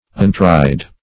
untried - definition of untried - synonyms, pronunciation, spelling from Free Dictionary